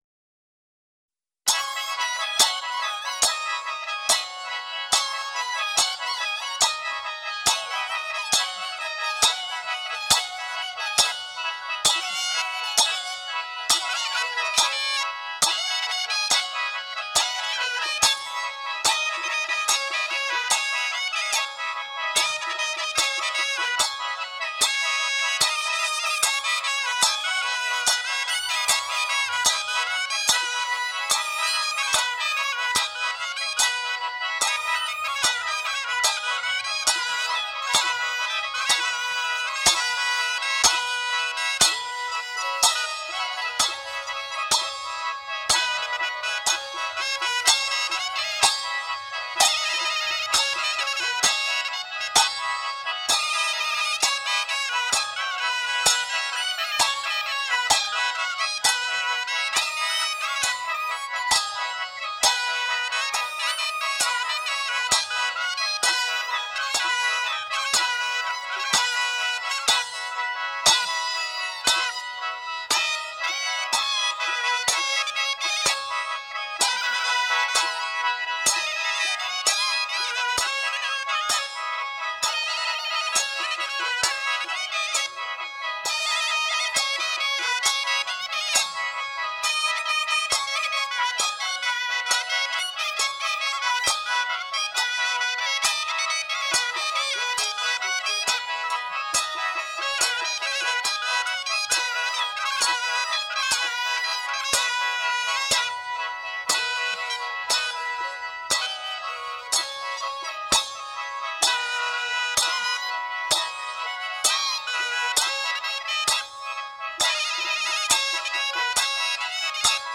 4) Sheng (organo a bocca): Aerofono ad ancia libera diffuso in prevalenza nel nord e nord-est del Paese.
L’ancia di ogni canna entra in vibrazione quando si chiude il foro digitale corrispondente, ed il suono è ottenuto tanto con l’espirazione che con l’inspirazione.
Ascolto: Sheng
pu’an zhou. Ensemble: sheng, guan (oboe), haidi (piatti).
cinaTrack-4-sheng.mp3